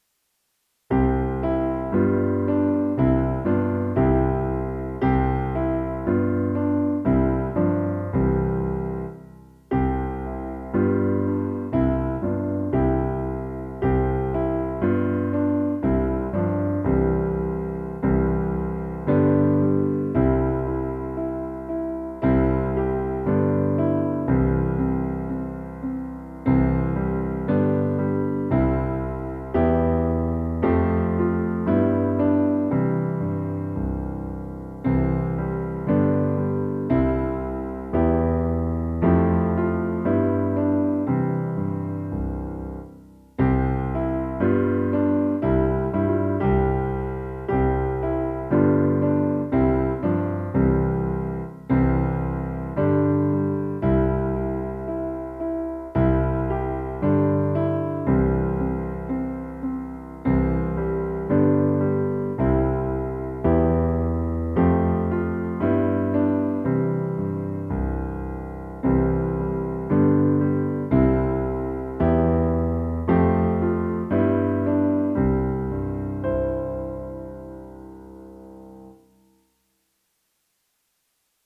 predlozky-klavirni-doprovod.mp3